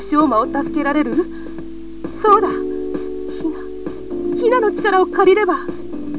Justice high school has captured Shoma Translation Clip taken from the game.